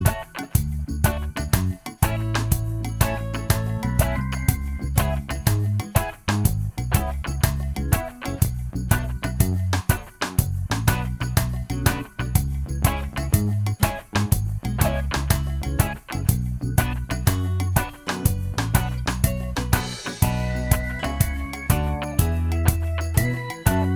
Minus Lead Guitar And Organ Reggae 3:43 Buy £1.50